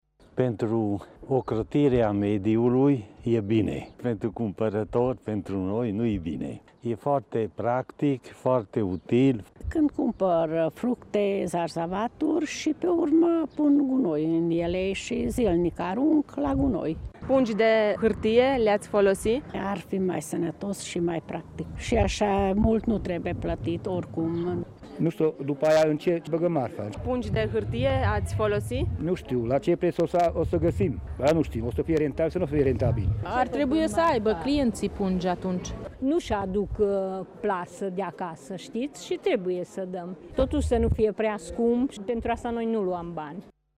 Vestea îi bucură doar pe cumpărătorii și comercianții cu spirit ecologic, cei comozi preferă pungile din plastic pentru utilitate: